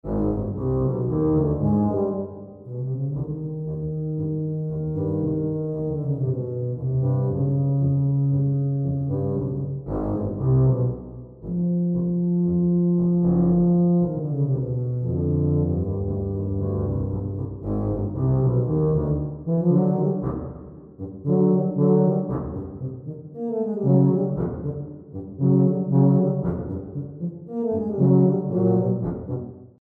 Voicing: Tuba Duet